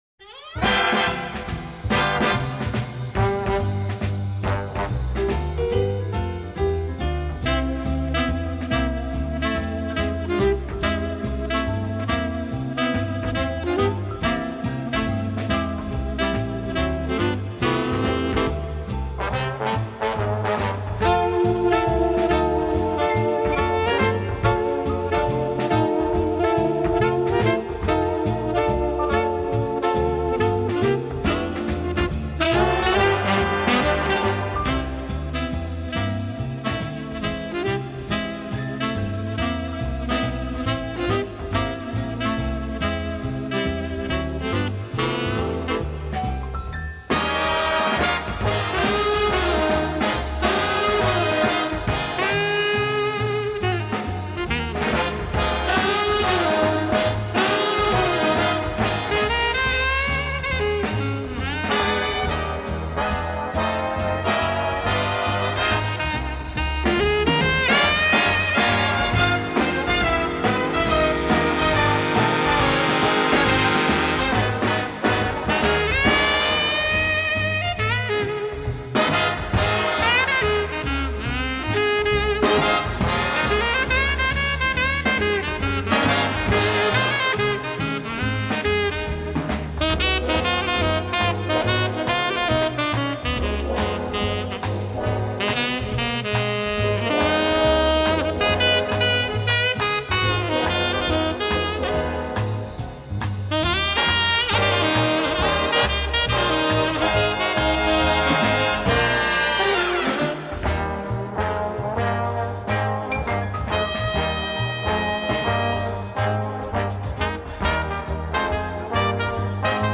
Жанр: Orchestral Pop, Instrumental